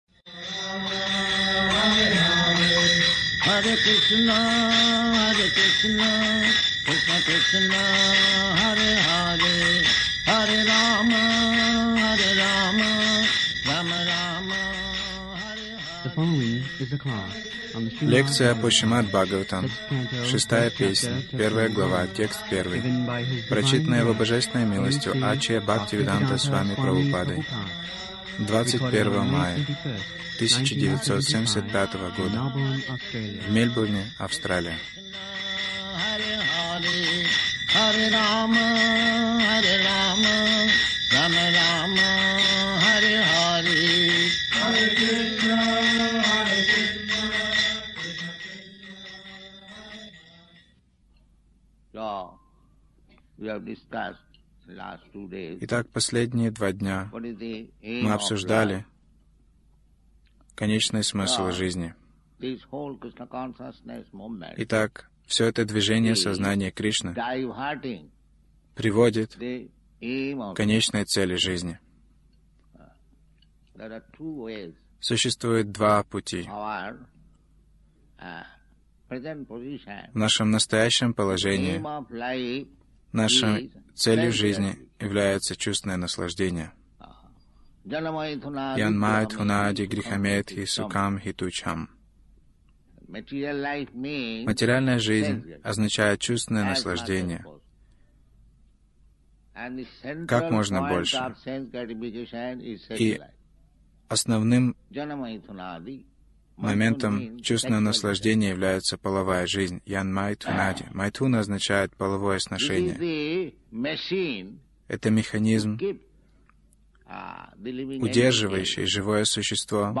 Милость Прабхупады Аудиолекции и книги 21.05.1975 Шримад Бхагаватам | Мельбурн ШБ 06.06.01 Загрузка...